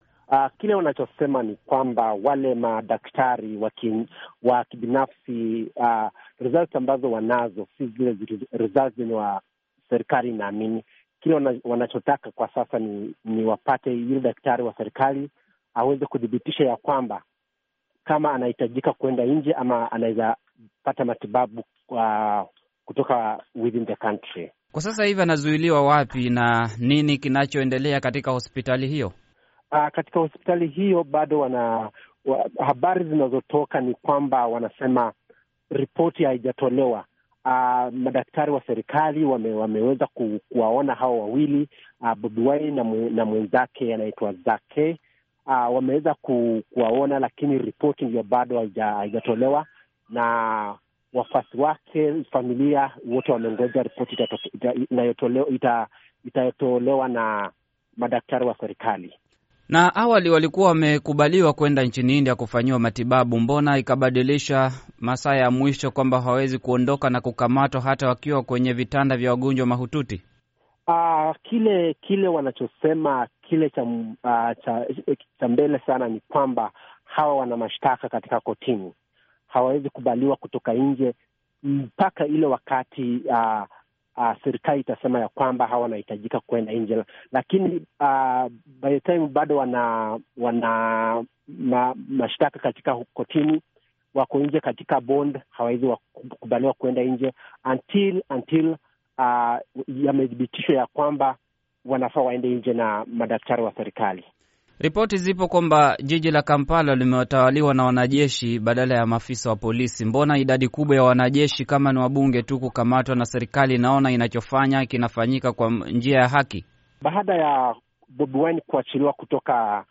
Mahojiano juu ya kukamatwa Bobi Wine, Uganda